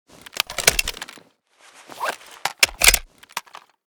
fnc_reload.ogg